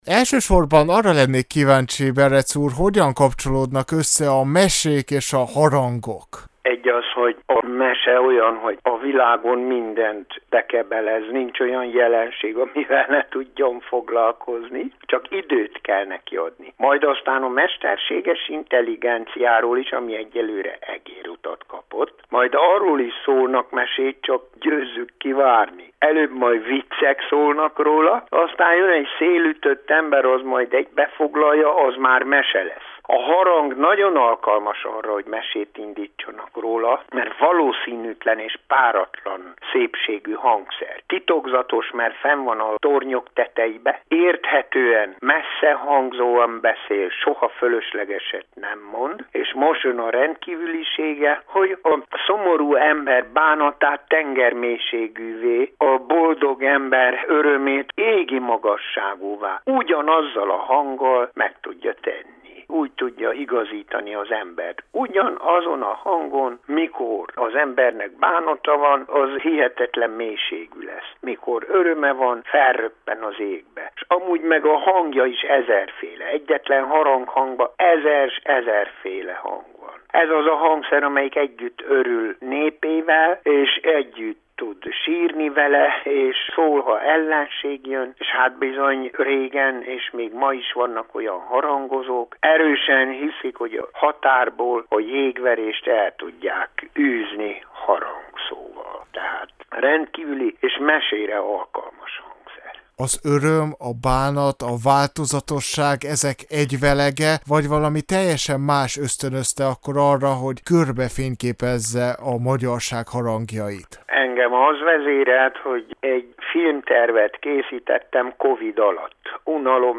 A mesék és a harangok összefonódásáról is beszélgettünk Berecz András, énekes-mesemondóval, a kiállítás megalkotójával.